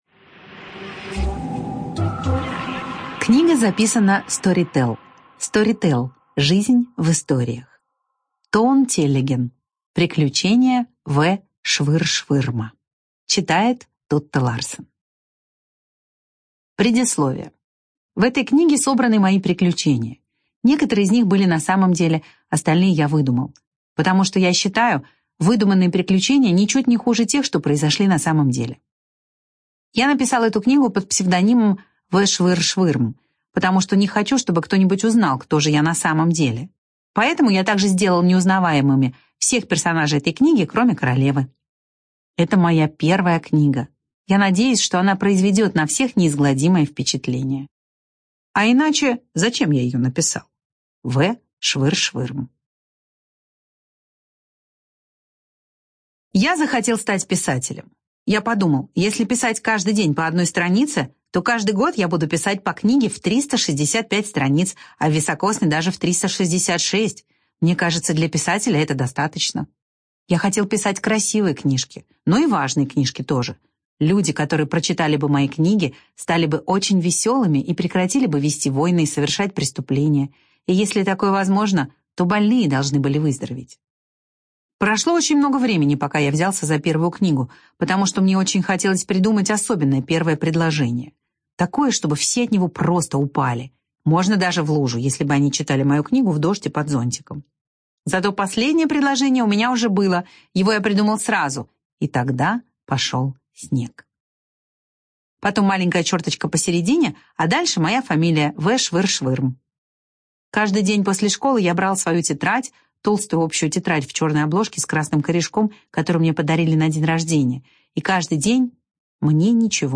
Студия звукозаписиStorytel